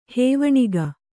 ♪ hēvaṇiga